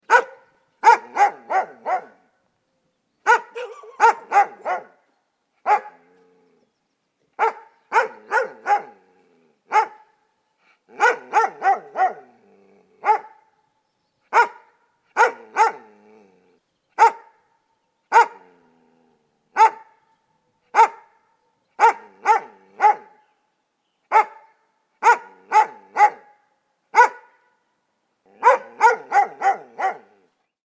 جلوه های صوتی
دانلود صدای سگ نر بزرگ و بالغ از ساعد نیوز با لینک مستقیم و کیفیت بالا